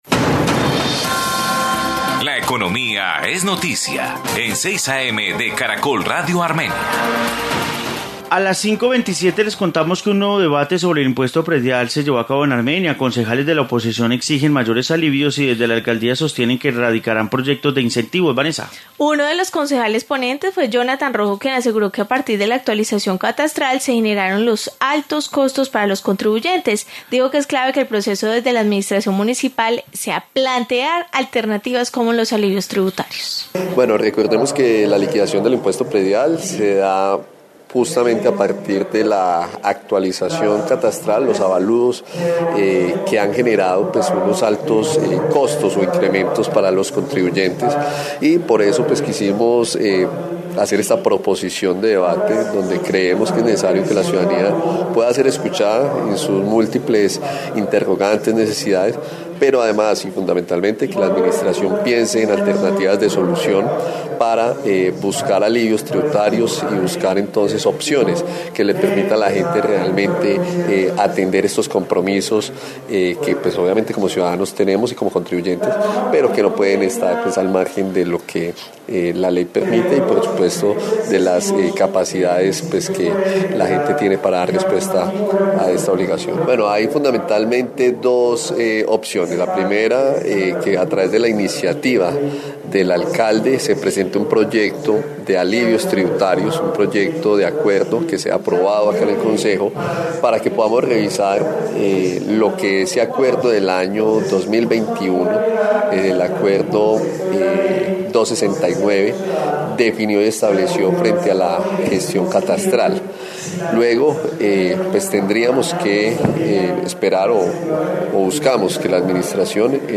Informe debate sobre predial